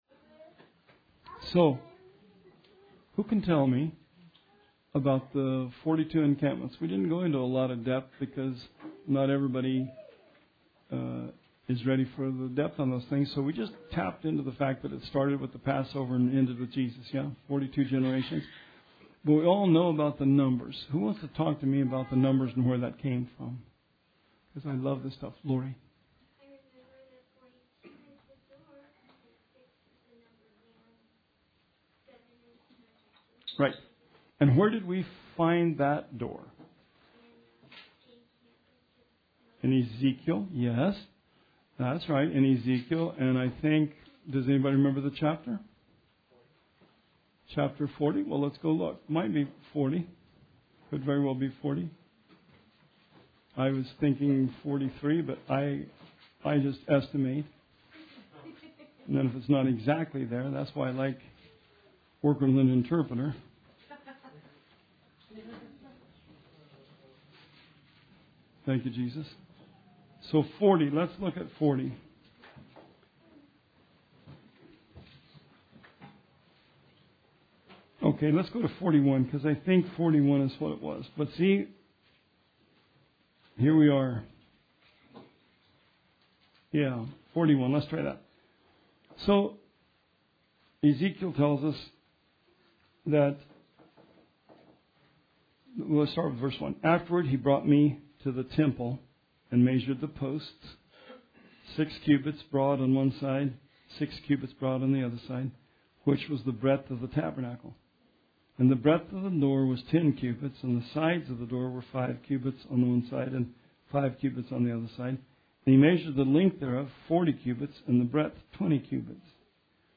Bible Study 2/6/19